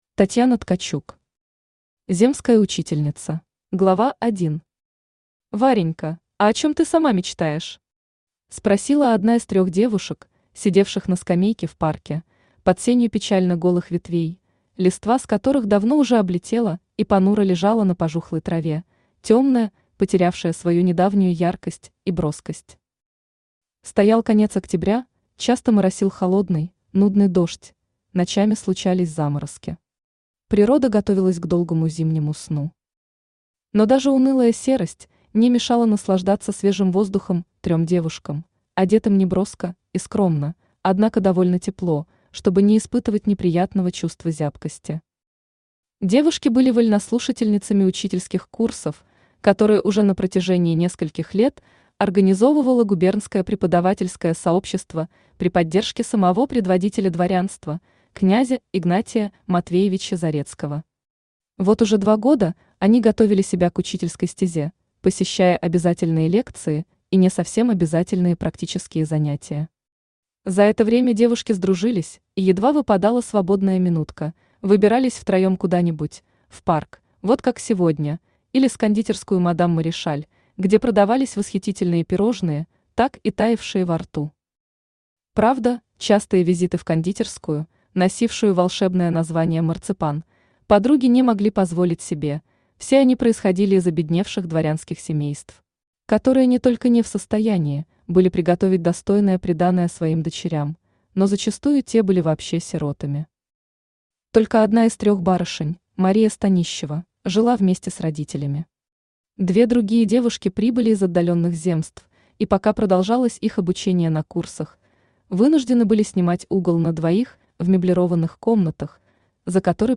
Аудиокнига Земская учительница | Библиотека аудиокниг
Aудиокнига Земская учительница Автор Татьяна Ткачук Читает аудиокнигу Авточтец ЛитРес.